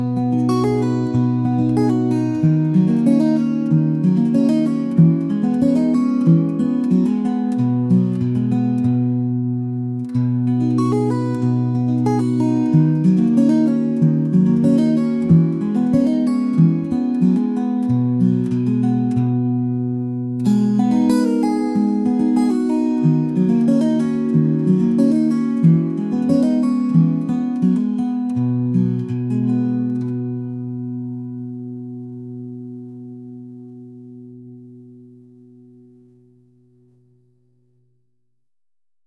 indie | folk | acoustic